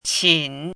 怎么读
qǐn
qin3.mp3